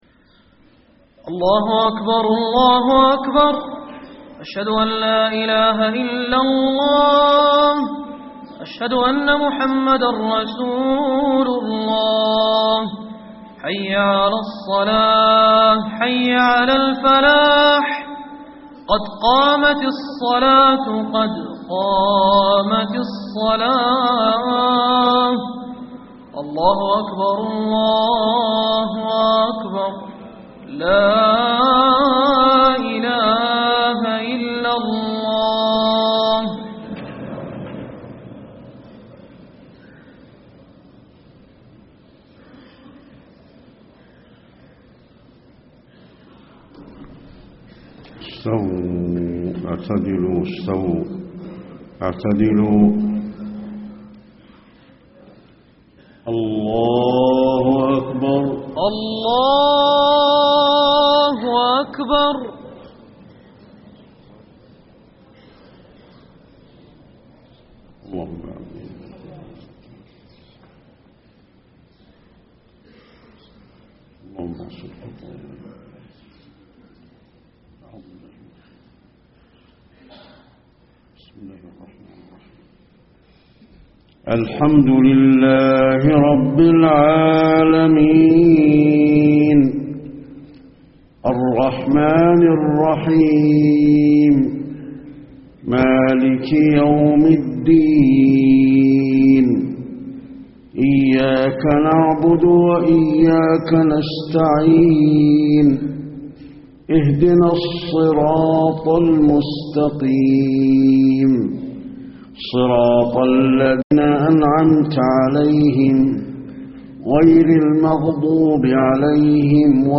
صلاة الفجر 4-6-1434 من سورة يس > 1434 🕌 > الفروض - تلاوات الحرمين